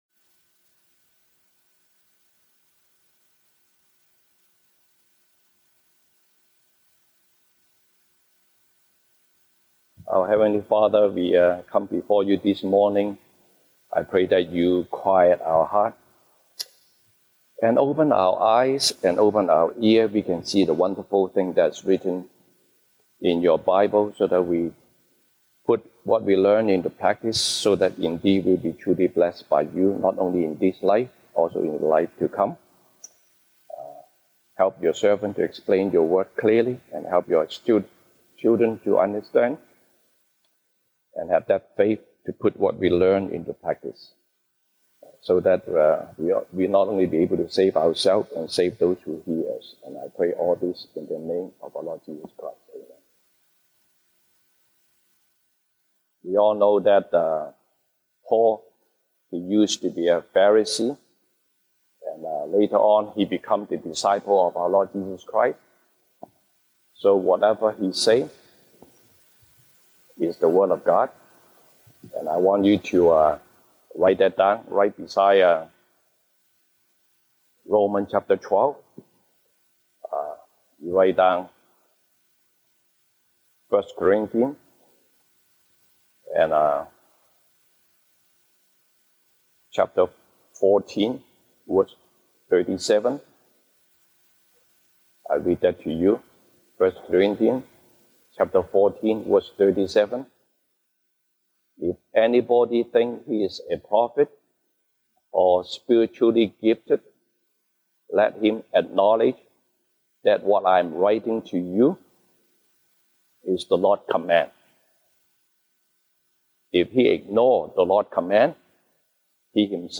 西堂證道 (英語) Sunday Service English: The fullness of Life